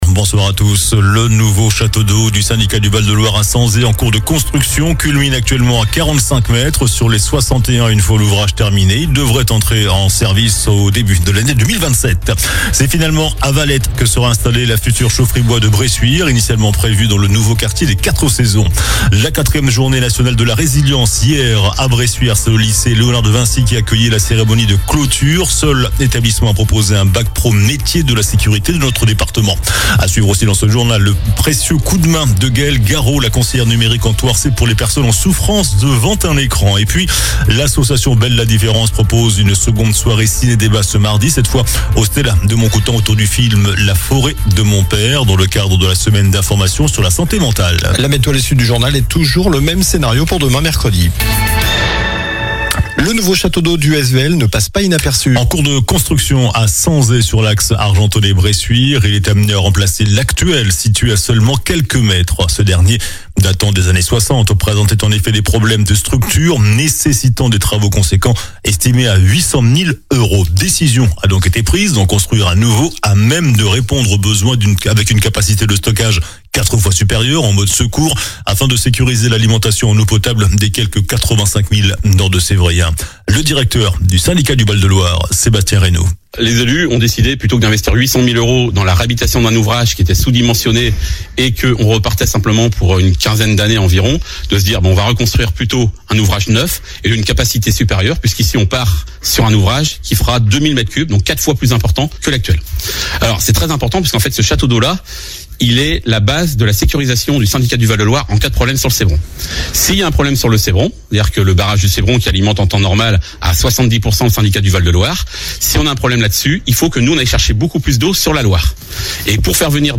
JOURNAL DU MARDI 14 OCTOBRE ( SOIR )